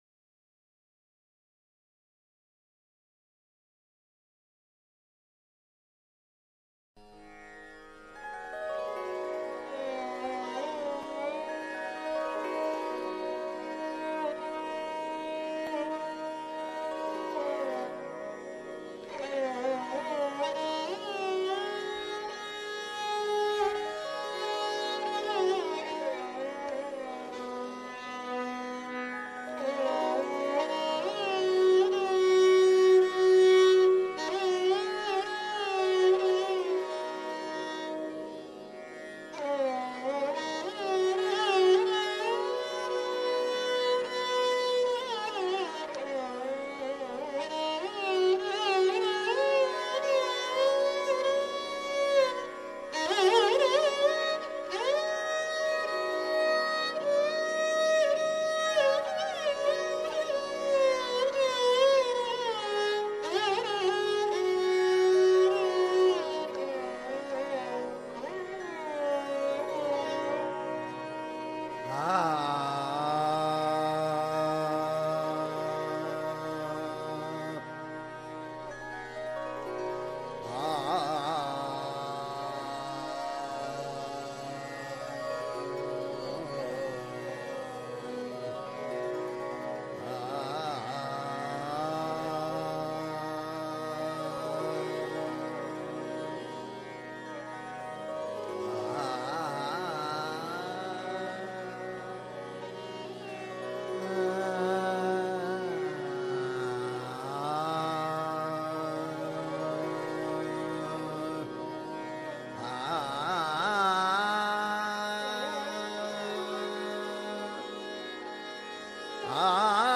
Audio – Sri Dasam Granth – Classical Raag
shri-dasam-granth-kirtan-classical-raag-kirtan-youtube.mp3